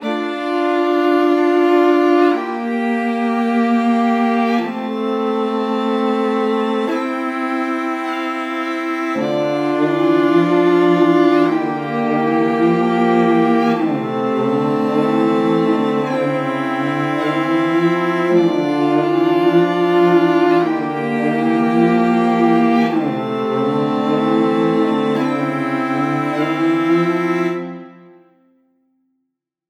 Categories: Melodies
20 High-Quality String Melodies Made Completely From Scratch.
Eternal_105BPM_Dmaj.wav